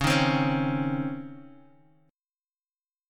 C#M7sus2 chord